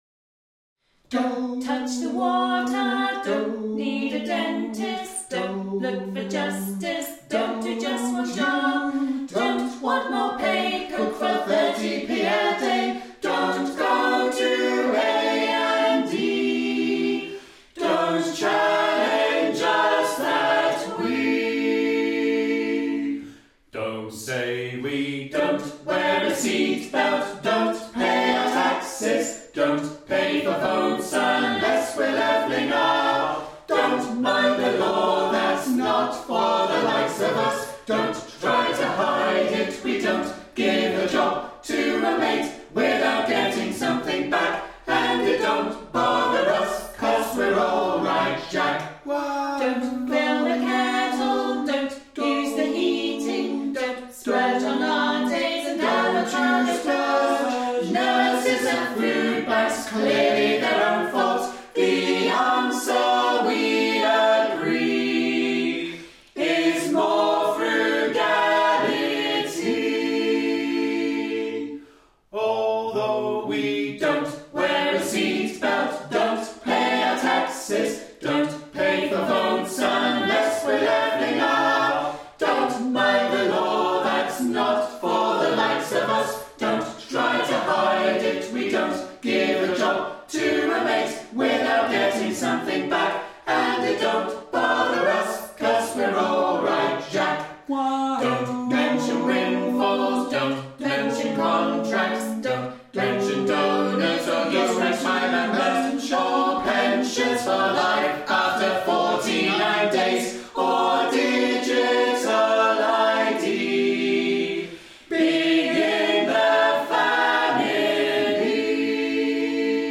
'Acapella folk at its best!'
Seven-piece acapella folk group from Bedfordshire, UK